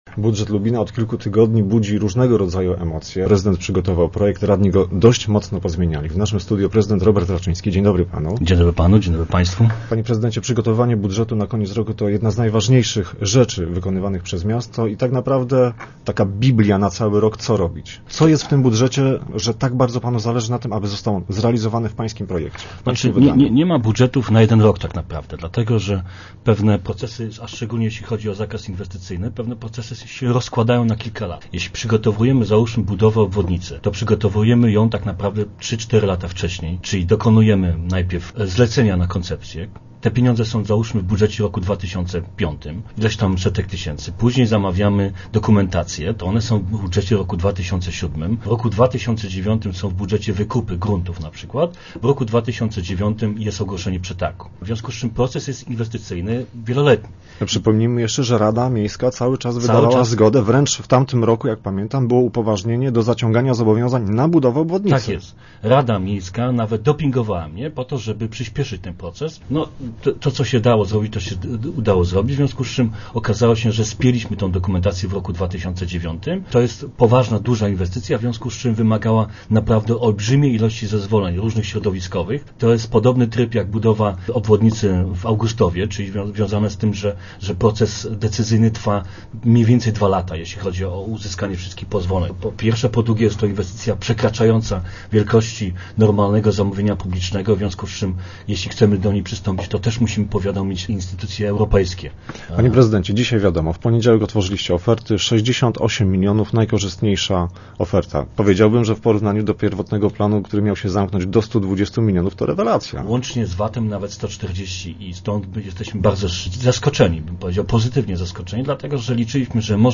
Prezydent w studiu Radia Elka potwierdził, że aby realizować dobre dla miasta projekty nie będzie prowadził żadnych negocjacji w kuluarach za zamknietymi drzwiami.